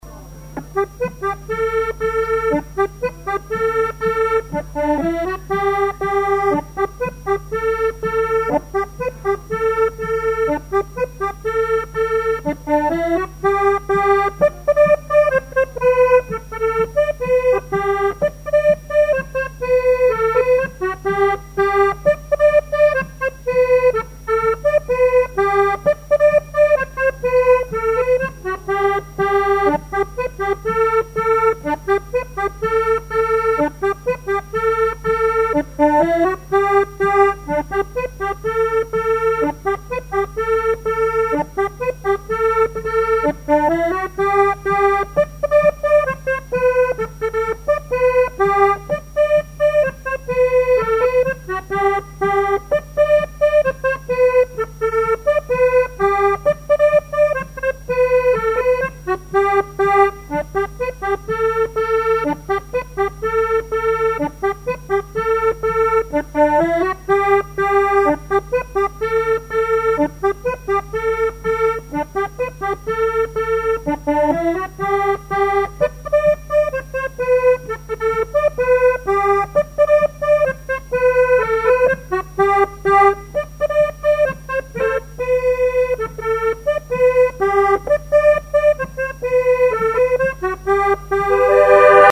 Genre brève
collectif de musiciens pour une animation à Sigournais
Pièce musicale inédite